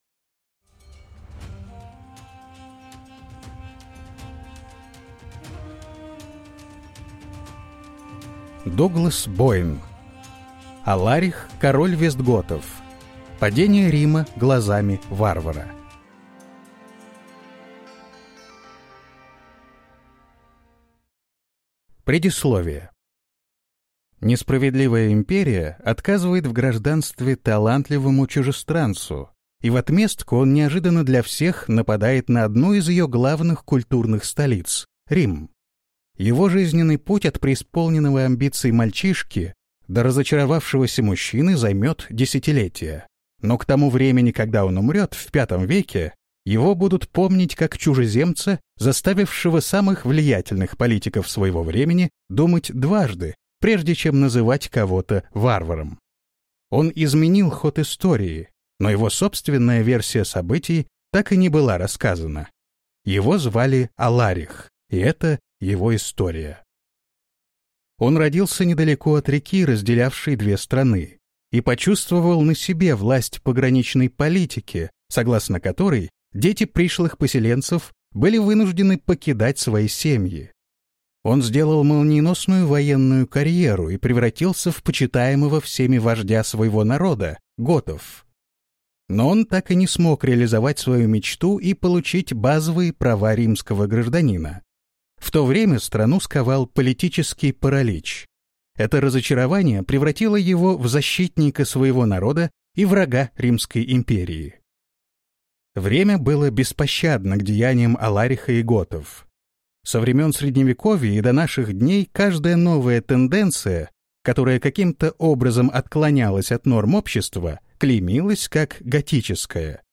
Аудиокнига Аларих, король вестготов: Падение Рима глазами варвара | Библиотека аудиокниг